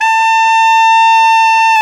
SAX TENORM0R.wav